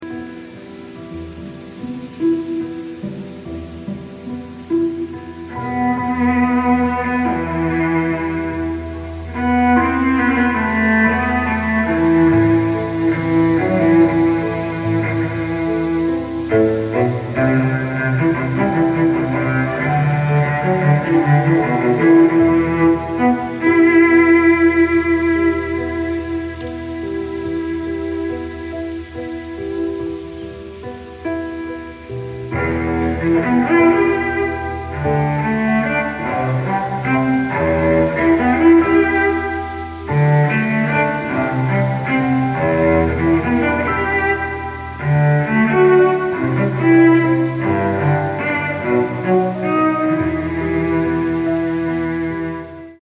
on cello 🎻🔥 Custom entrance = unlocked core memory.